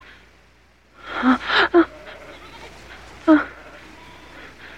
• woman whimpering sound effect.ogg
[woman-whimpering-sound-effect]_myw.wav